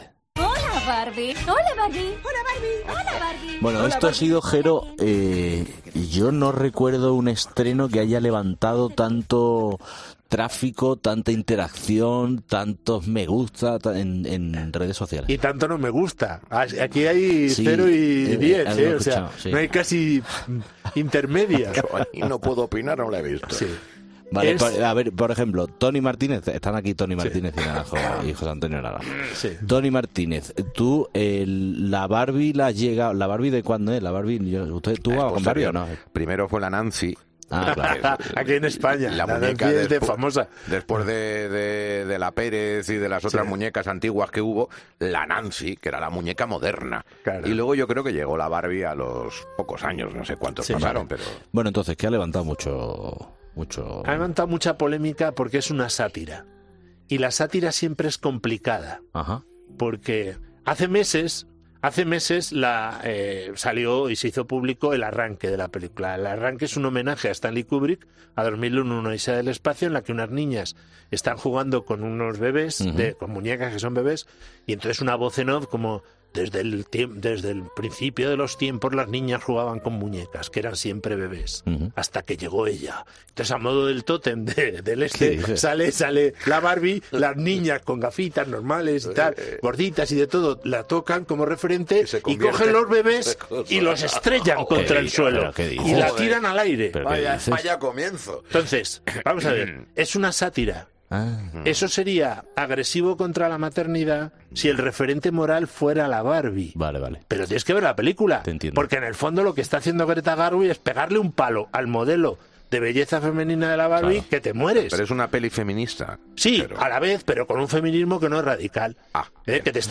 En una entrevista en 'Herrera en COPE' ha contado su opinión sobre la nueva película y ha desvelado detalles sobre su colección